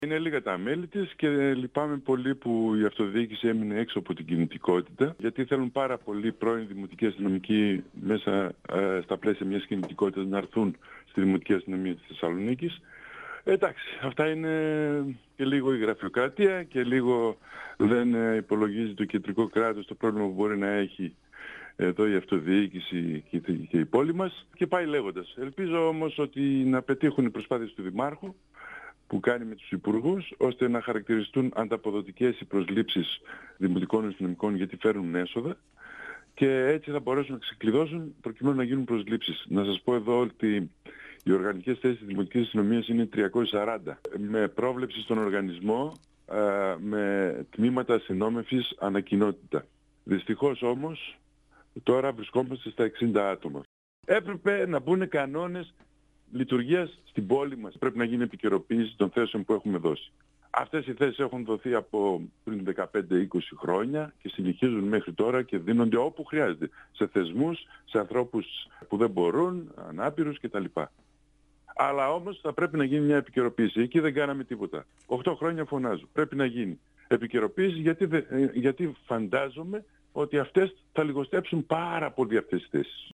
Ο  Λευτέρης Κιοσέογλου, εντεταλμένος δημοτικός σύμβουλος για θέματα Δημοτικής Αστυνομίας στον 102FM του Ρ.Σ.Μ. της ΕΡΤ3
Συνέντευξη